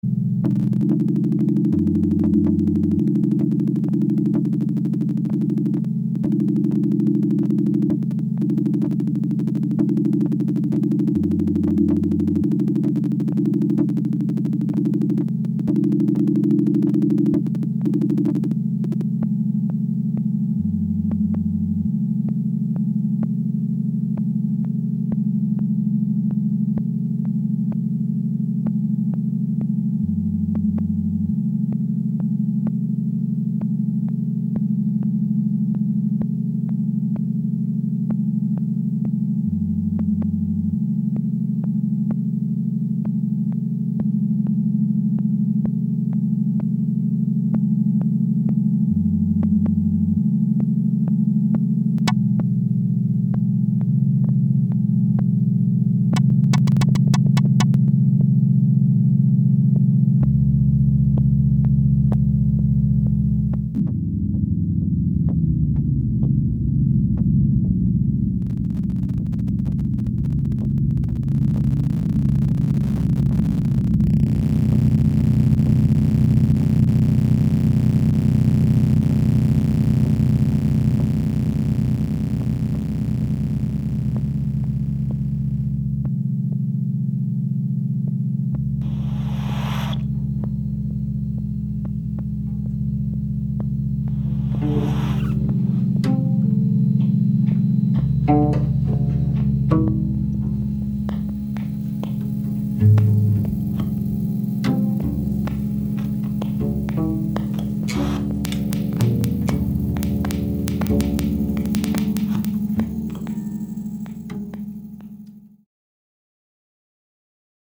elektro-akustische Improvisations-Vierer
hochfrequentes Laptop-Knarzen und Knistern
akustische Gitarre